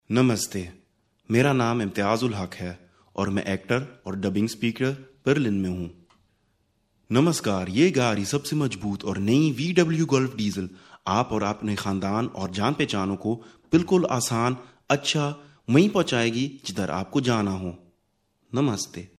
Casting Hindi